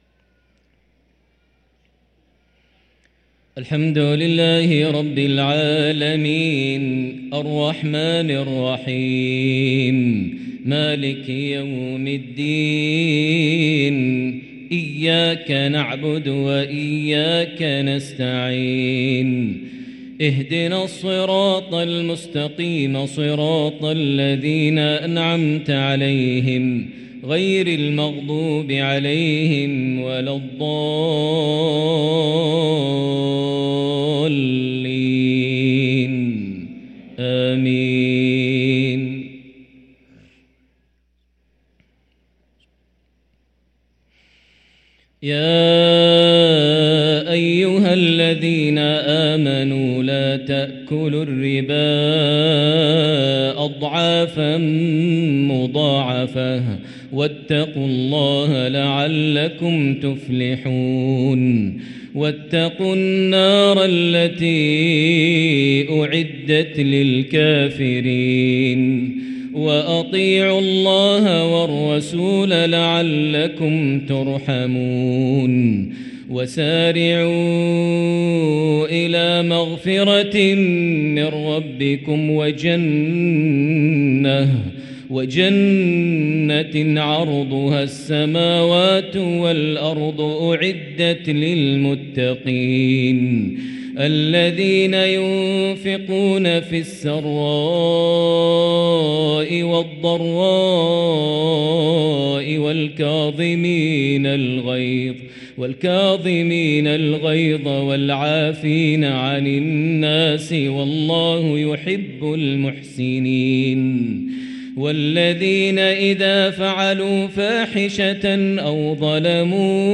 صلاة العشاء للقارئ ماهر المعيقلي 3 شعبان 1444 هـ